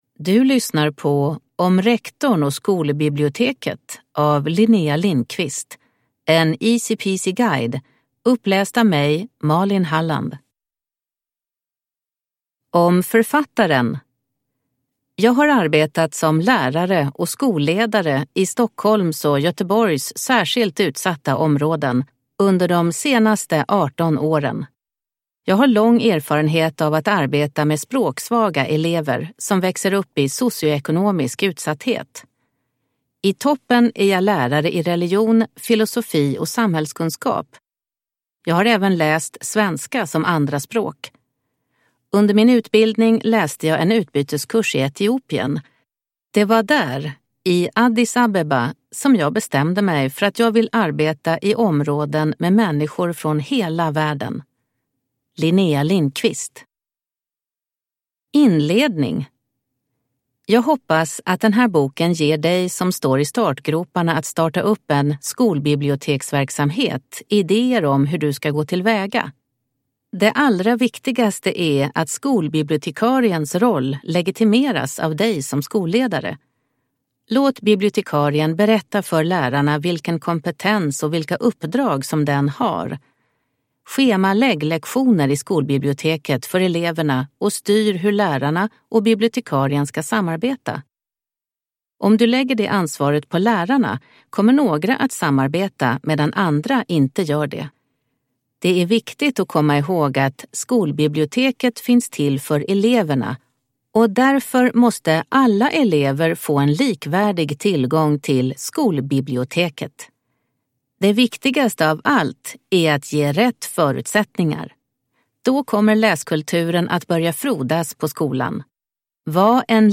Om rektorn och skolbiblioteket – Ljudbok